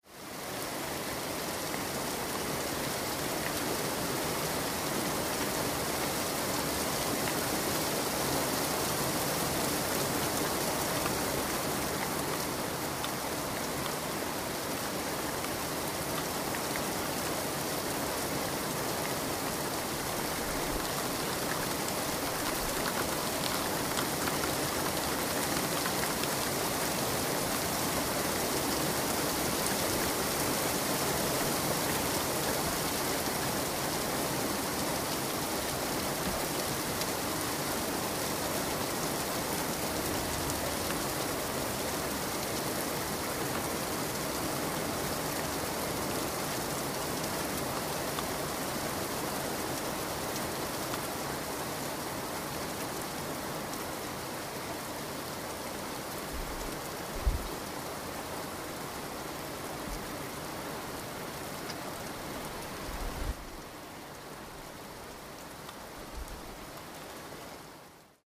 Intense rain